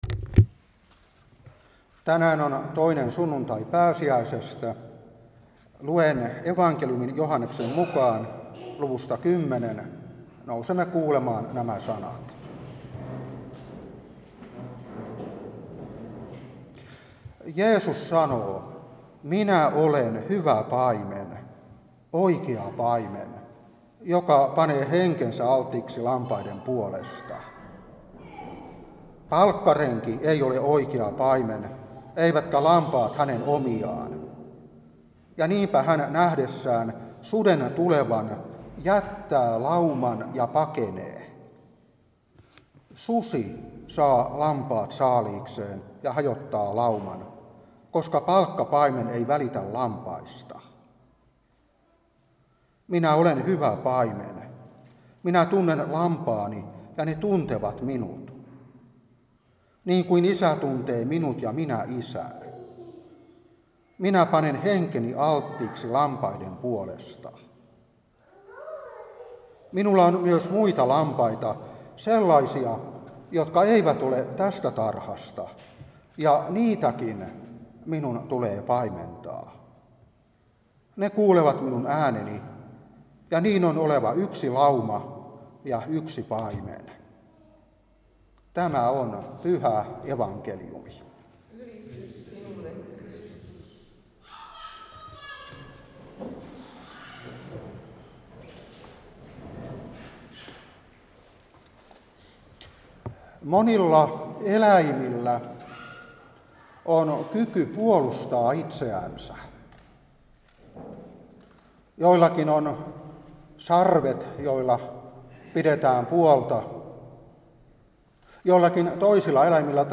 Saarna 2022-5.